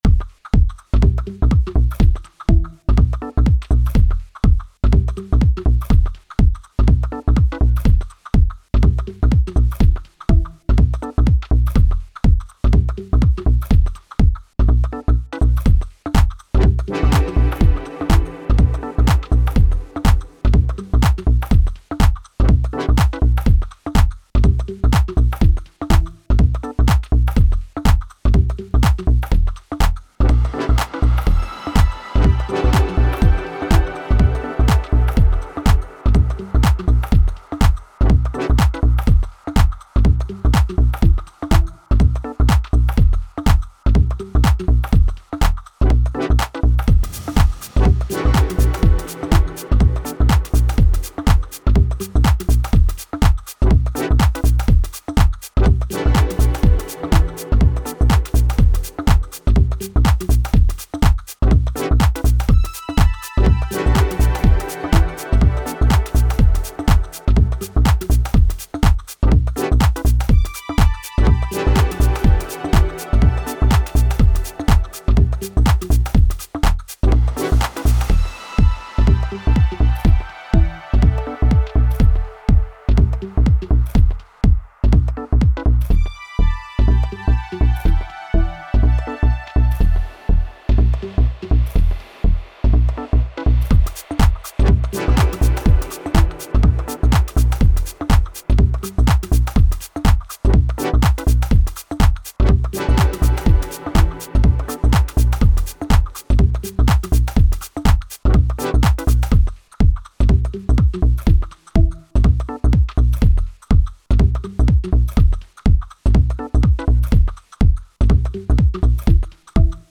All melodic sounds are Massive X presets. Drums a mishmash of TR-8, Syntakt & foley.